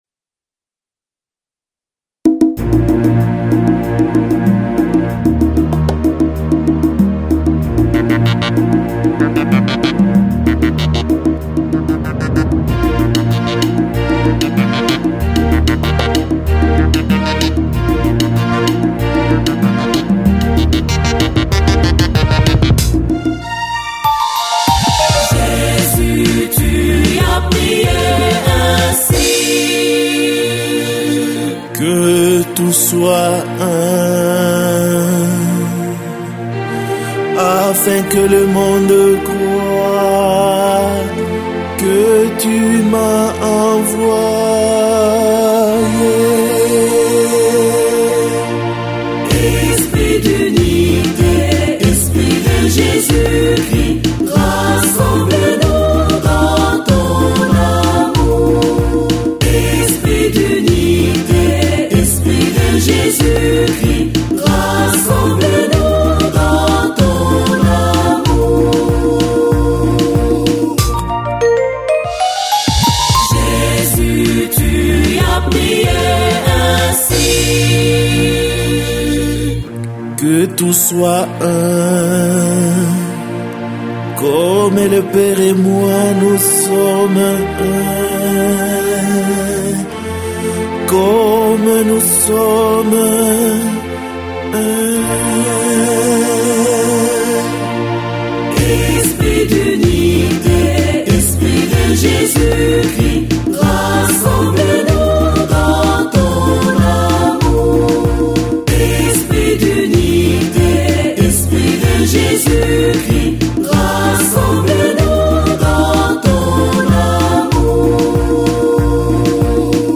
Cantique